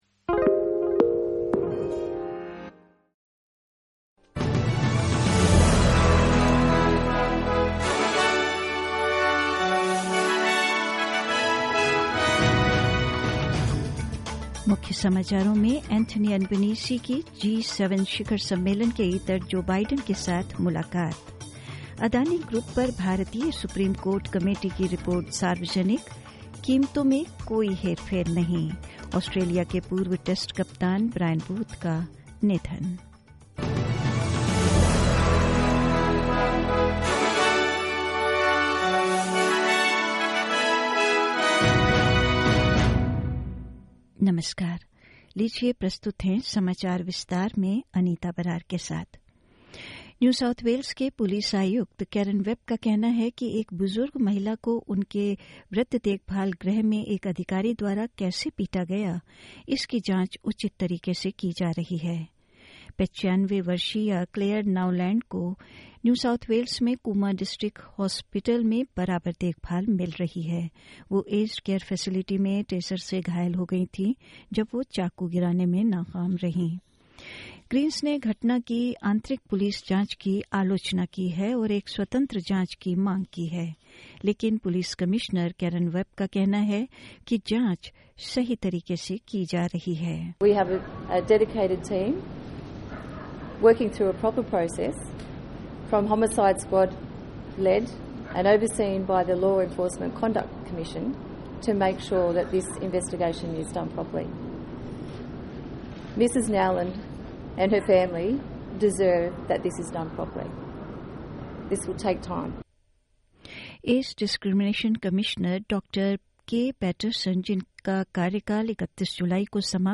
In this latest Hindi bulletin: Anthony Albanese meets with Joe Biden on the sides line of the G7 summit; Australia's 31st men's Test captain, Brian Booth, has died aged 89; India's SC panel report finds no price manipulation in Adani Group and more news.